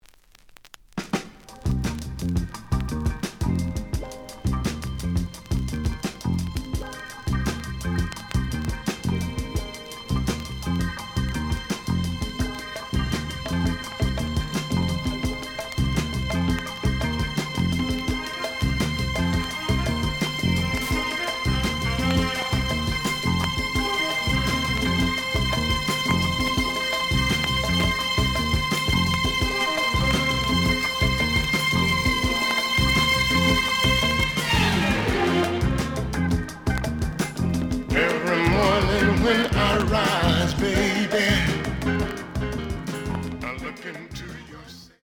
The audio sample is recorded from the actual item.
●Genre: Funk, 70's Funk
Some click noise on both sides due to scratches.